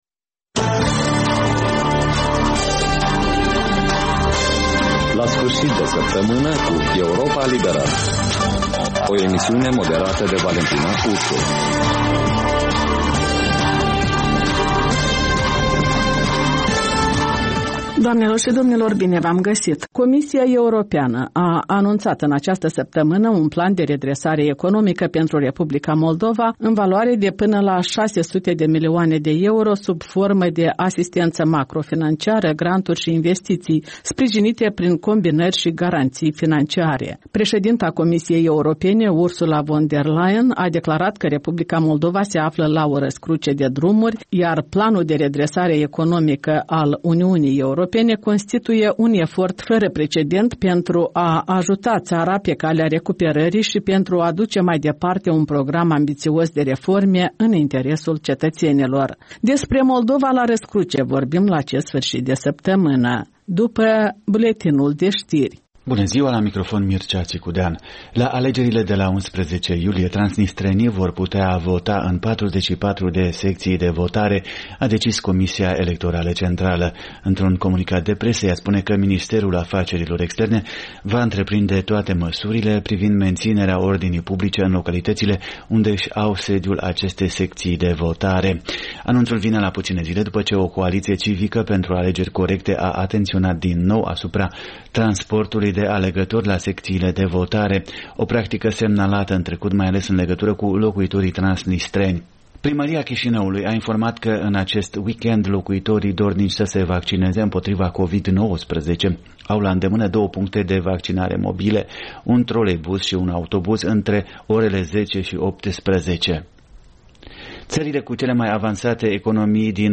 In fiecare sîmbătă, un invitat al Europei Libere semneaza „Jurnalul săptămînal”.